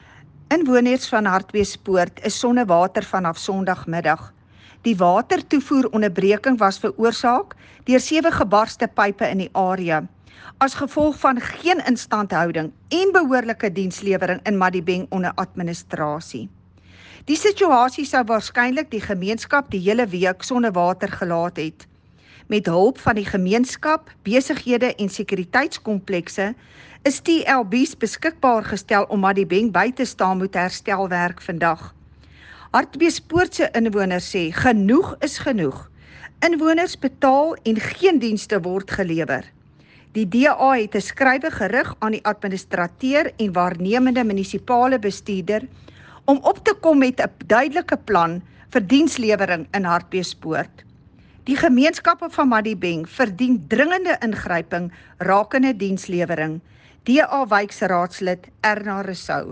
Afrikaans by Cllr Erna Rossouw, the DA Councillor in Madibeng Local Municipality.
Erna-Rossouw-Afrikaans-Voice-Note.mp3